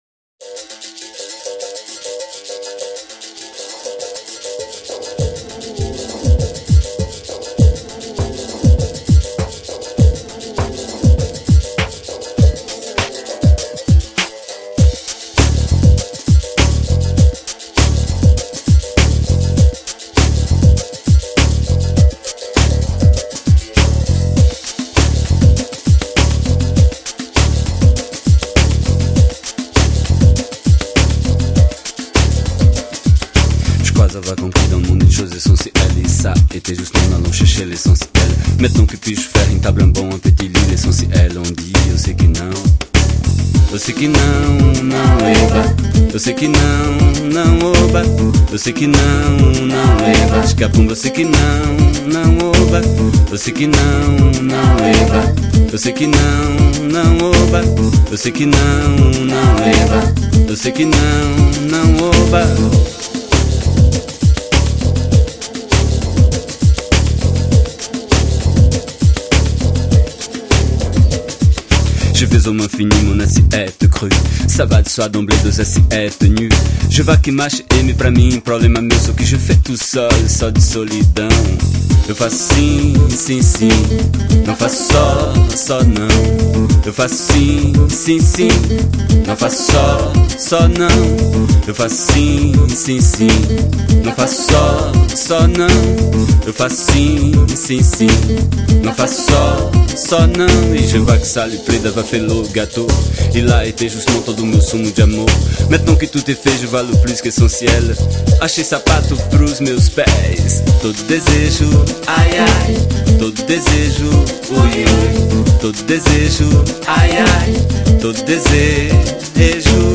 Genre : MPB